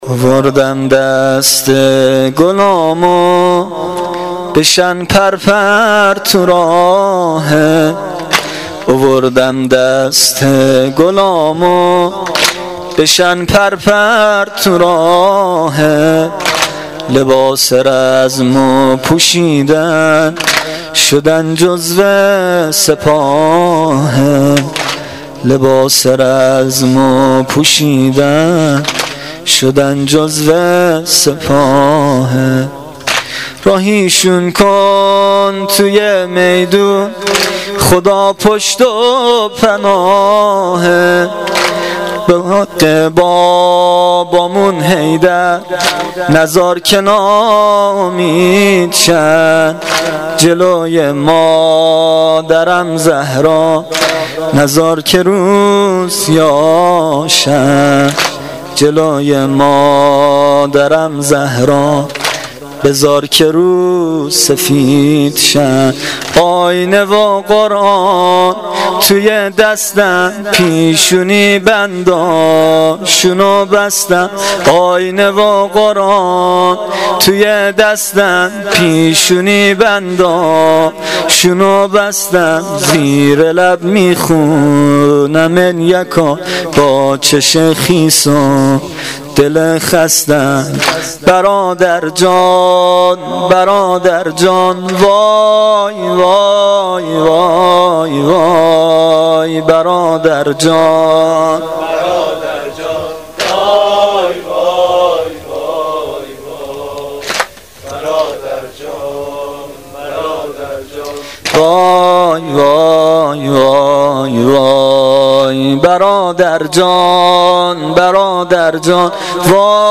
واحد سنگین شب چهارم محرم الحرام 1396
روضه